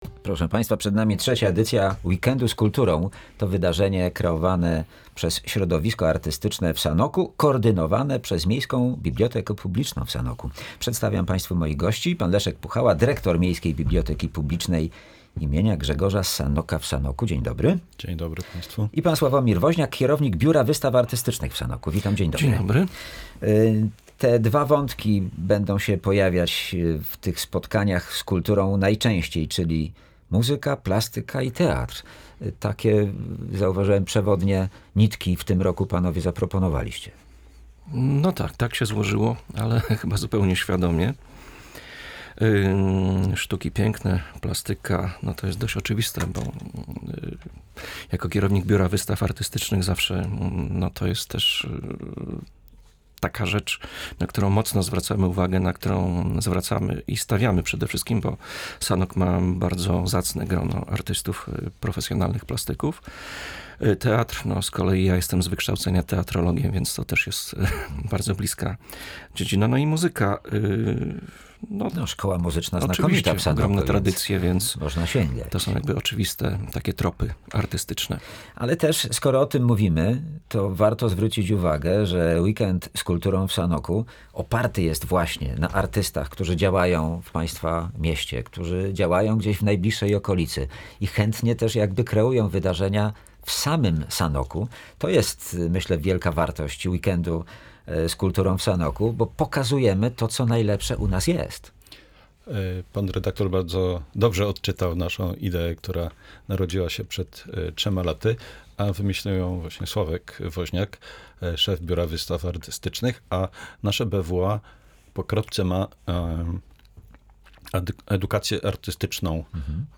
Sanok już wkrótce stanie się tętniącym życiem centrum artystycznych wydarzeń. W związku ze zbliżającym się Weekendem z Kulturą, w audycji „Tu i Teraz” mieliśmy przyjemność porozmawiać z wyjątkowymi gośćmi, którzy współtworzą to inspirujące wydarzenie: